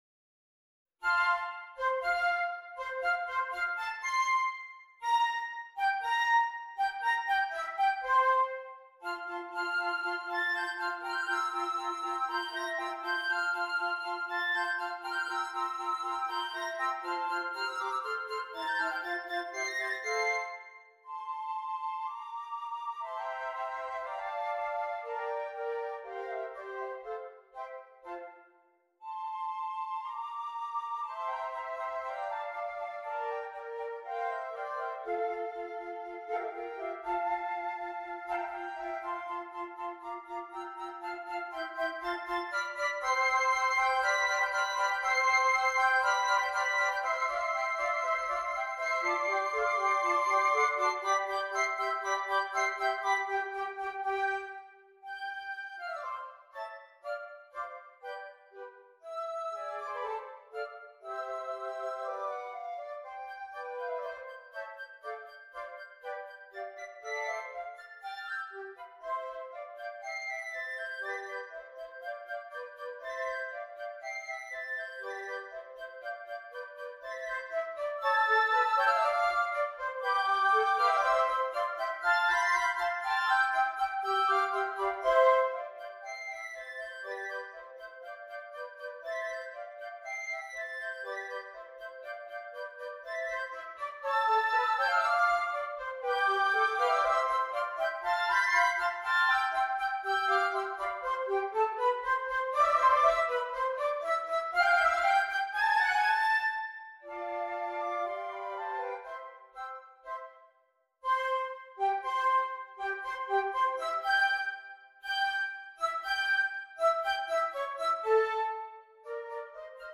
6 Flutes